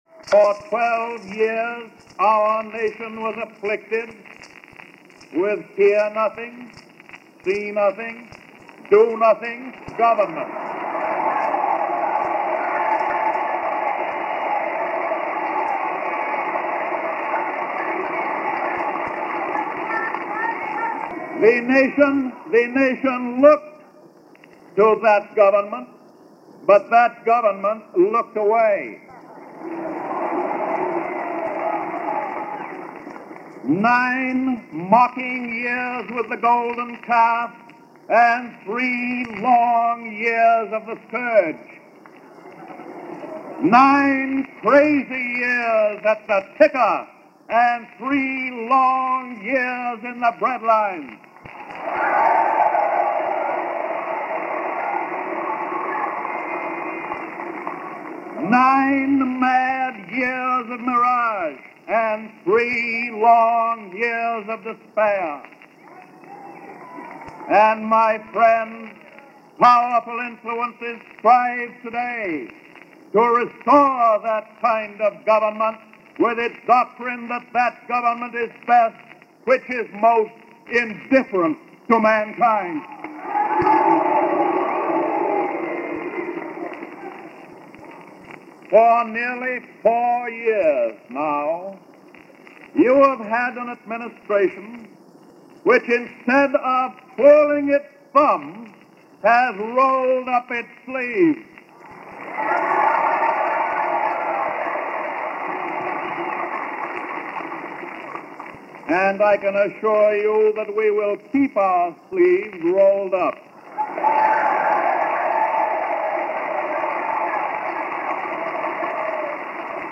FDR – Address At Madison Square Garden – 1936
FDR-Madison-Square-Garden-1936.mp3